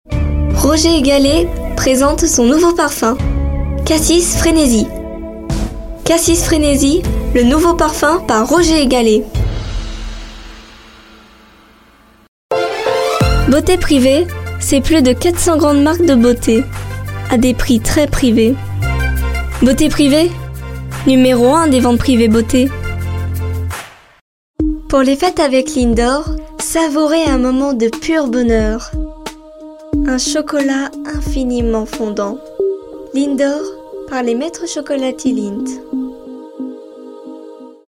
Bandes-son
Voix off
18 - 22 ans - Mezzo-soprano Soprano